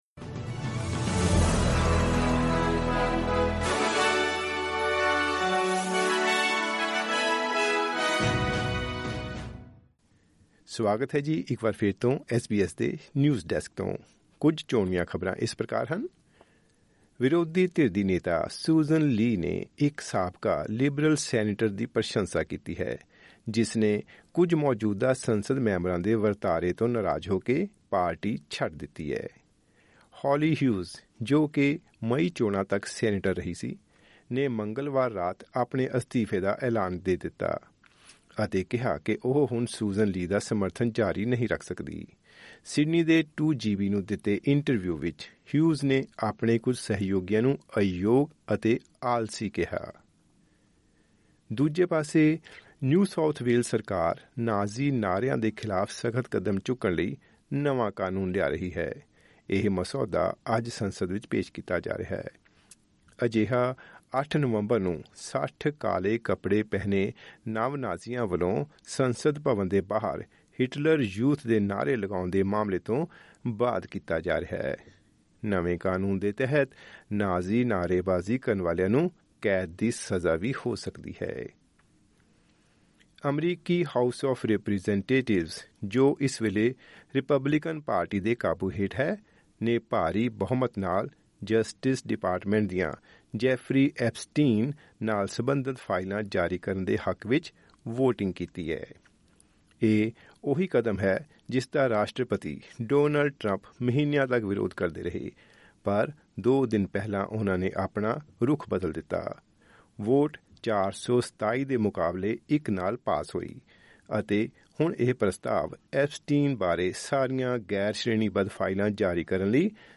ਖ਼ਬਰਨਾਮਾ: ਅੰਤਰਰਾਸ਼ਟਰੀ ਵਿਦਿਆਰਥੀਆਂ ਲਈ ਫੈਡਰਲ ਪੁਲਿਸ ਦੀ ਚੇਤਾਵਨੀ